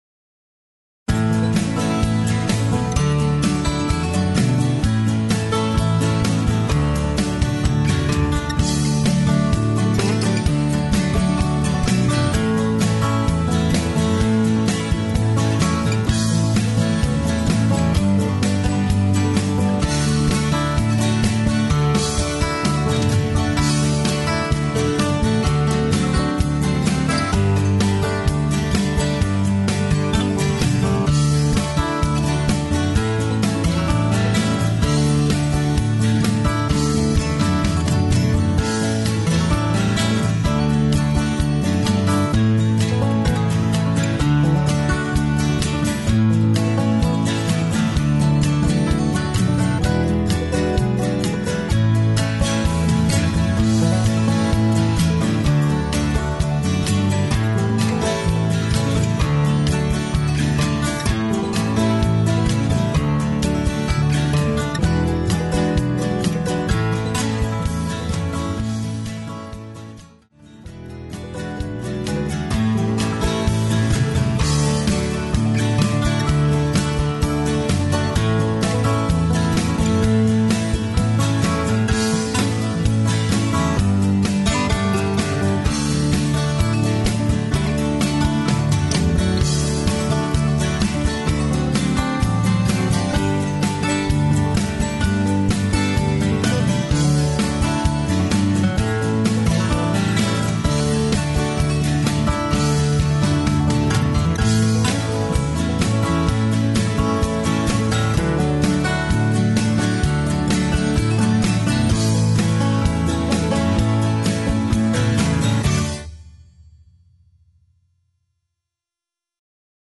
Instrumental TRacks
No Meldoy